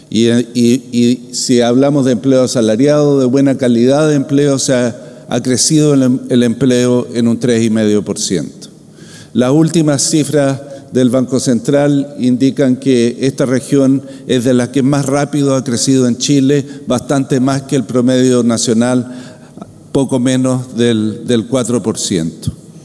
Lo anterior ocurrió en medio del Encuentro Regional de la Empresa (Erede), desarrollado en Concepción, región del Bío Bío, el cual contó con la presencia del Ministro de Economía y Energía, Álvaro García, y el titular de hacienda, Nicolás Grau.
En su discurso, el biministro aseguró que la región crece a un ritmo más acelerado que el resto de las regiones, y que, a pesar del cierre de Huachipato y el plan de industrialización, se logró contrarrestar la cesantía.